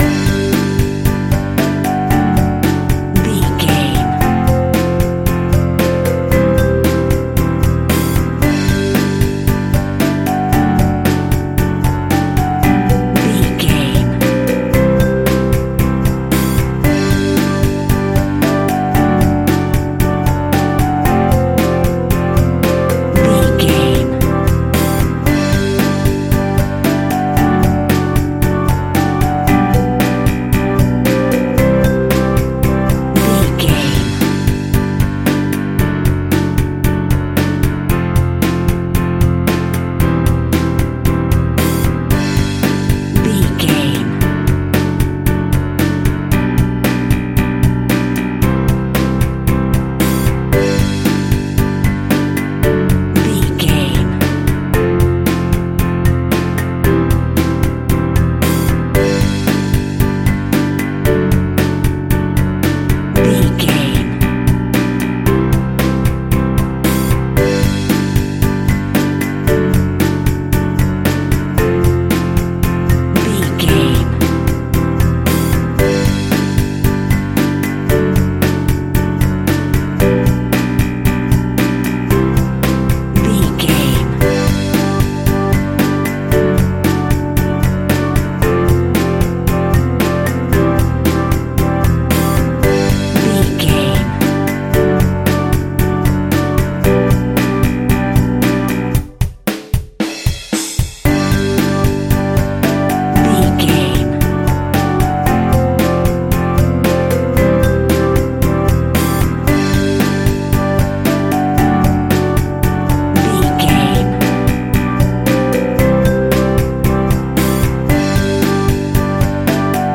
Ionian/Major
instrumental music
electronic
drum machine
synths
strings
orchestral
brass